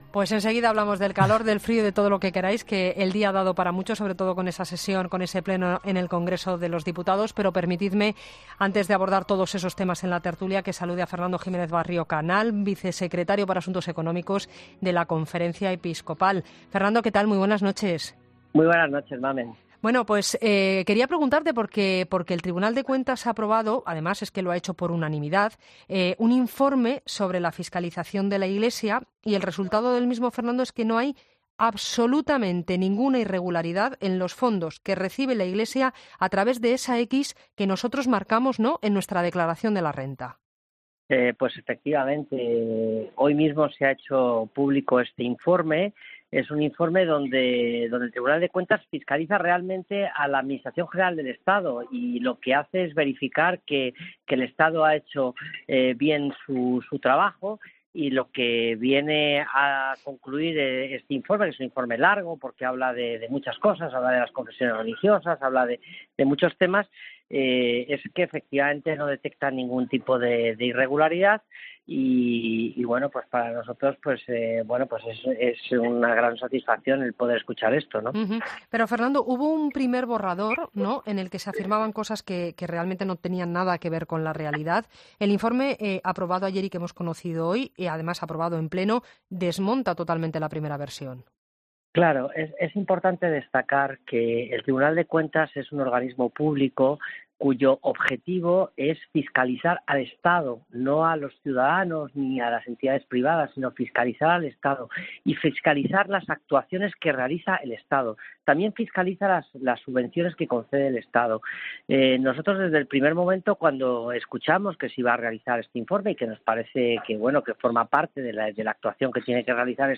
En una entrevista este miércoles en “La Linterna”